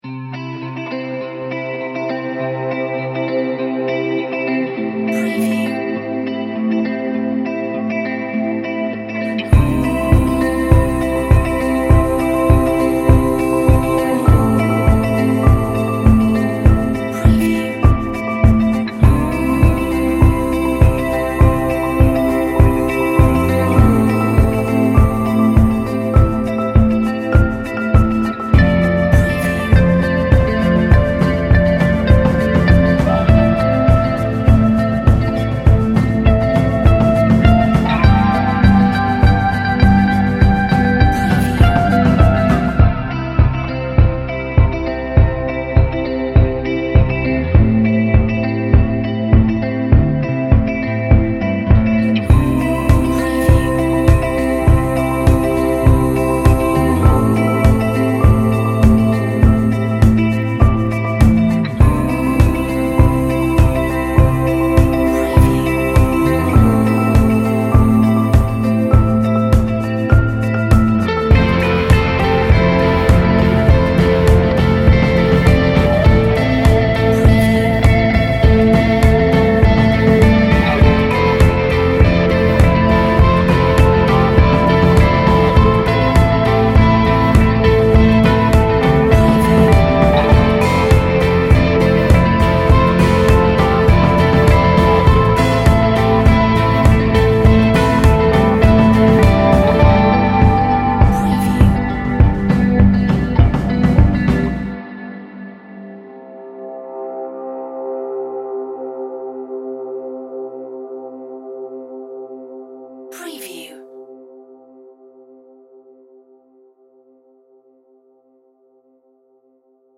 Melodic guitars